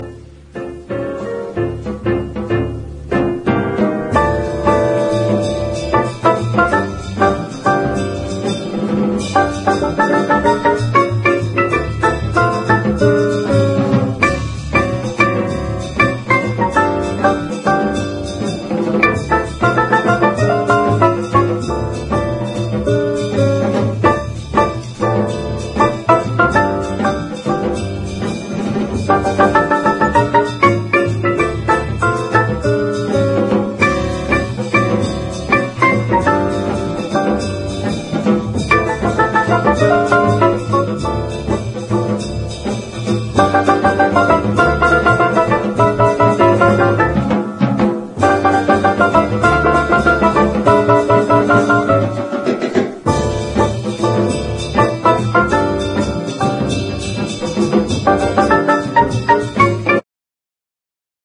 レアなジャズ・ファンク/レアグルーヴ盛り沢山の超即戦力コンピレーション！